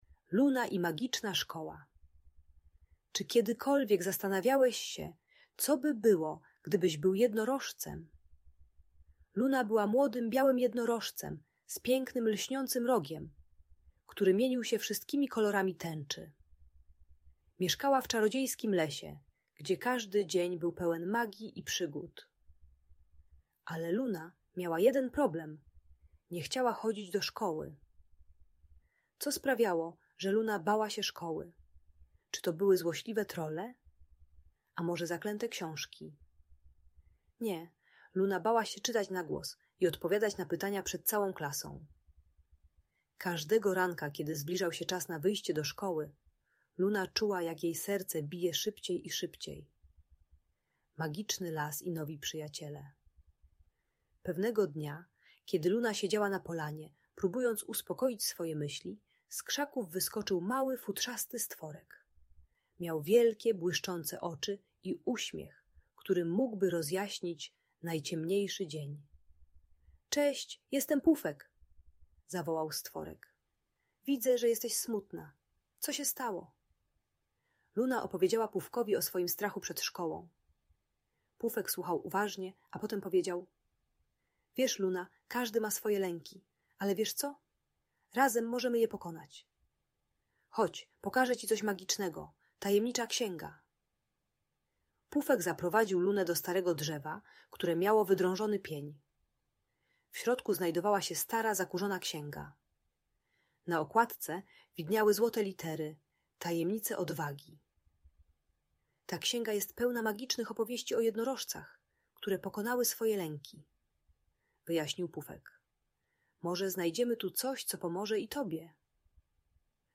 Historia Luny i Magicznej Szkoły - Audiobajka